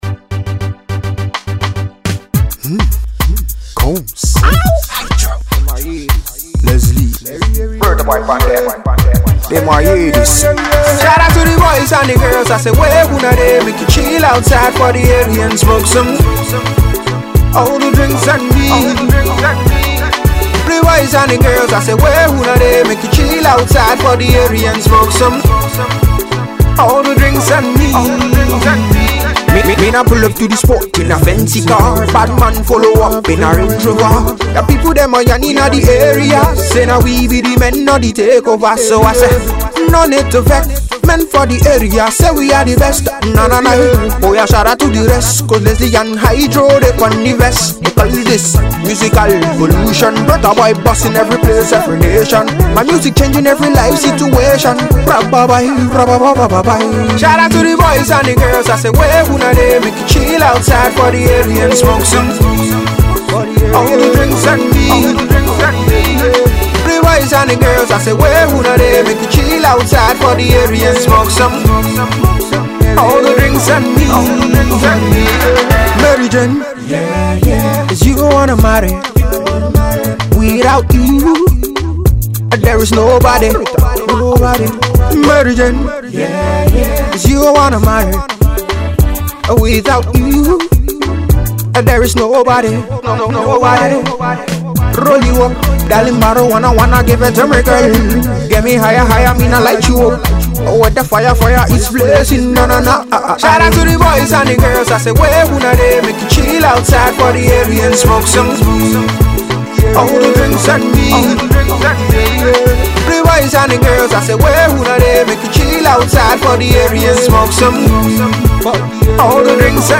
brand new banging tune